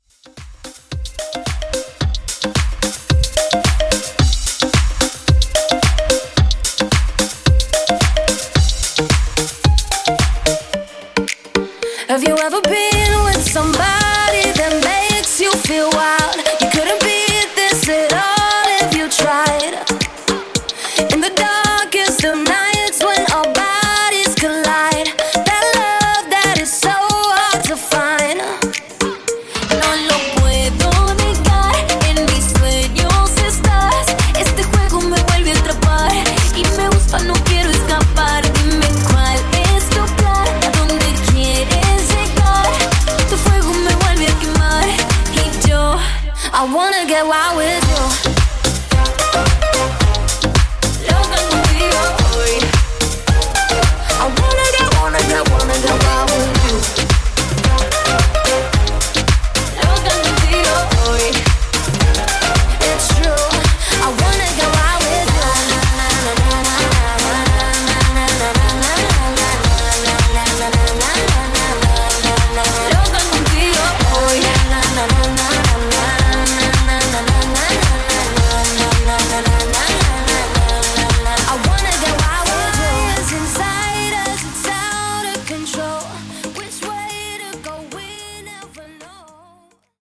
Dance Club Redrum)Date Added